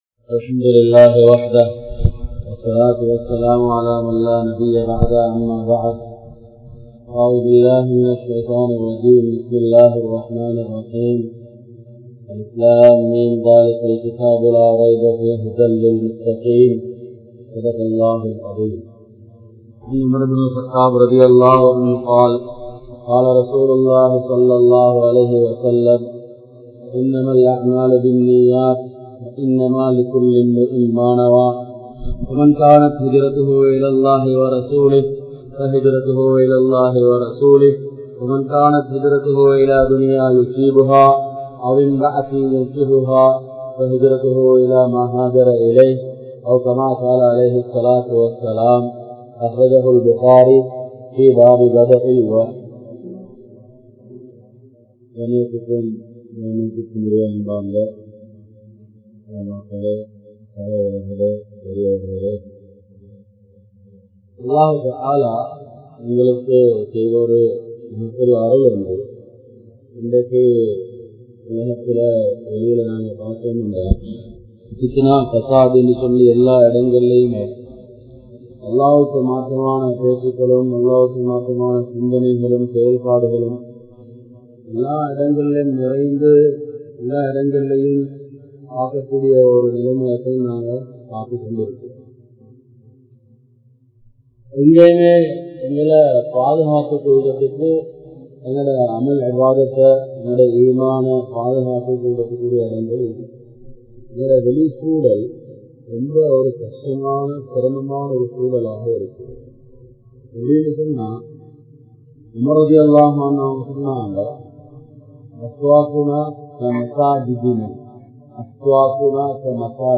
Indraya Vaalifarhalin Nilamai | Audio Bayans | All Ceylon Muslim Youth Community | Addalaichenai
Majmaulkareeb Jumuah Masjith